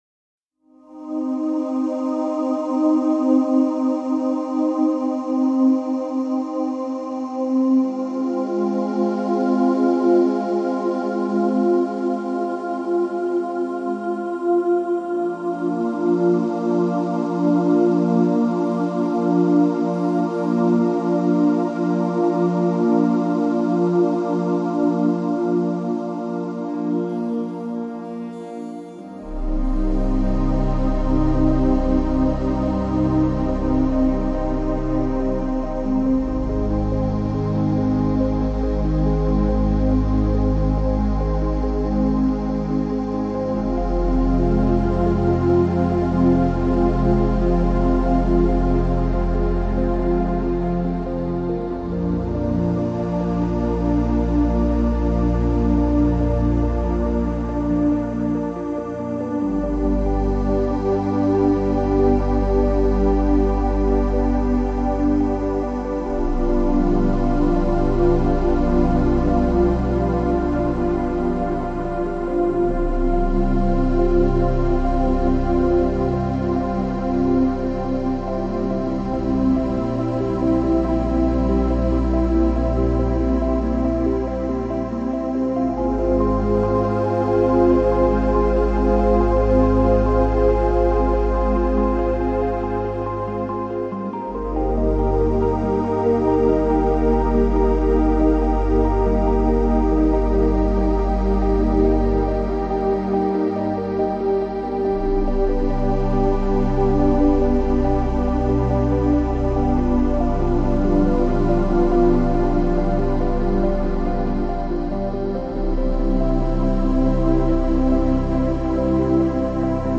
New Age | Ambient